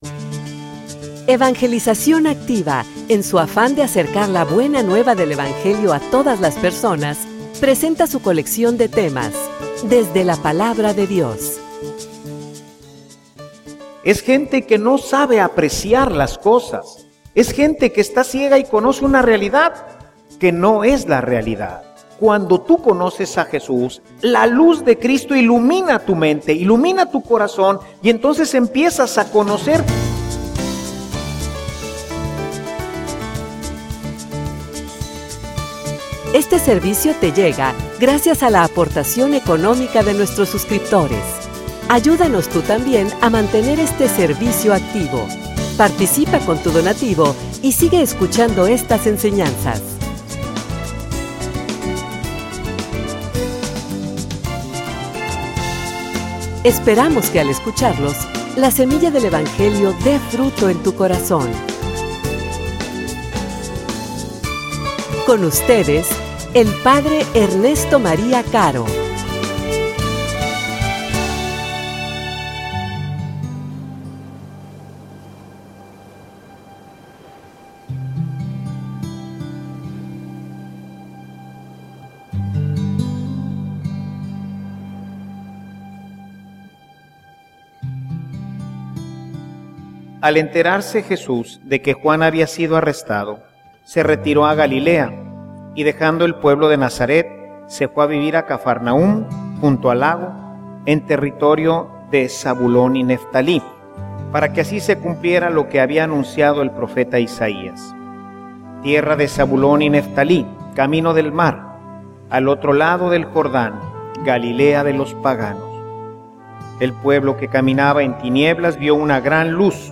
homilia_Llevales_la_luz.mp3